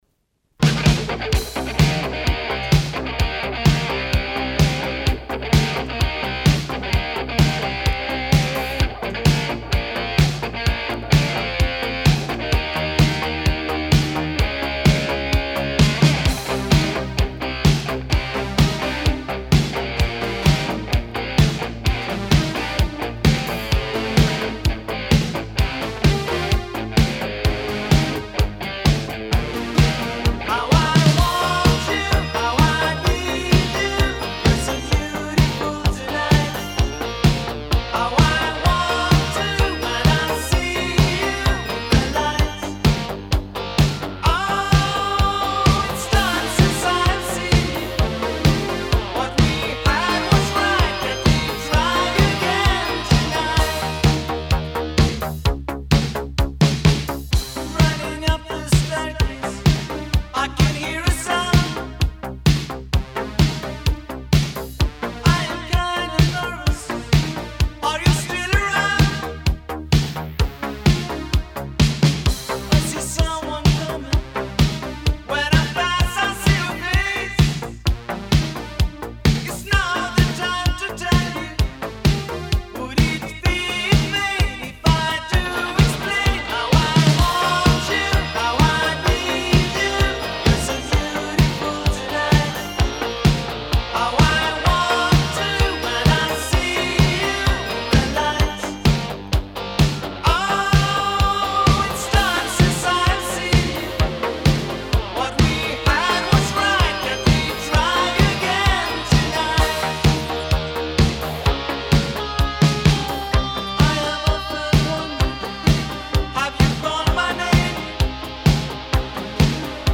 Disco Italo Disco